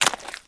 STEPS Swamp, Walk 06.wav